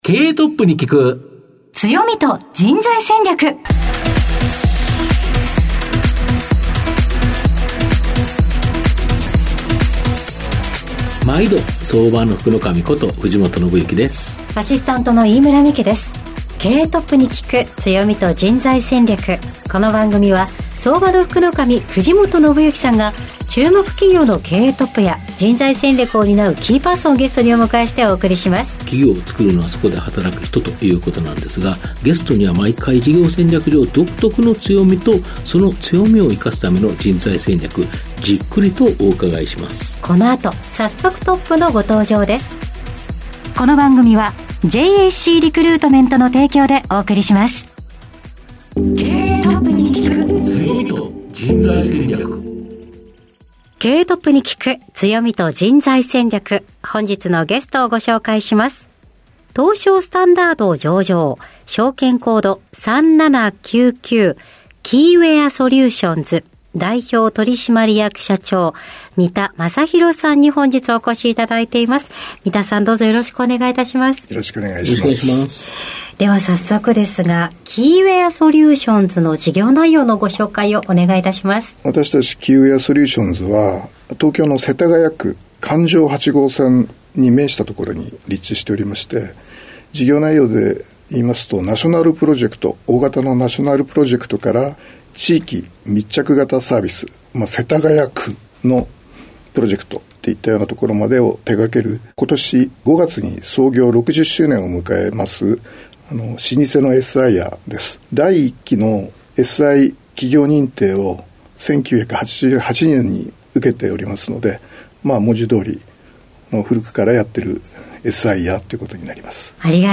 毎回注目企業1社をピックアップし経営トップをゲストにお招きし、事業の側面だけでなく人材戦略の観点からも企業の強みに迫る。トップの人柄が垣間見えるプライベートなQ&Aも。